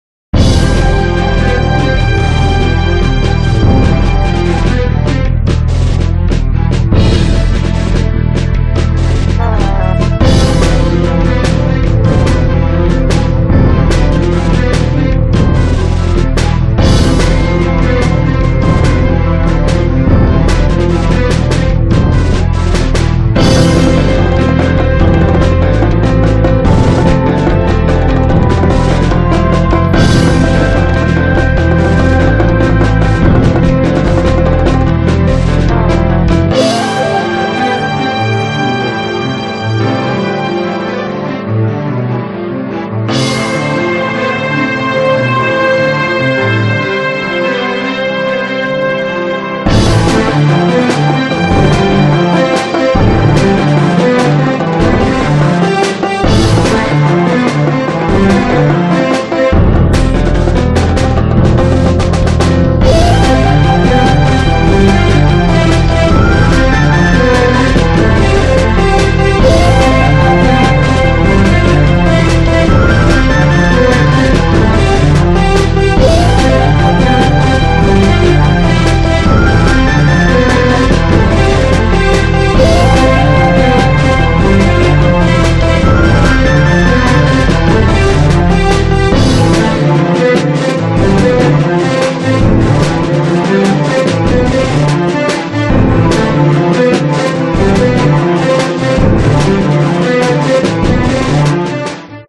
BPM146
Audio QualityPerfect (Low Quality)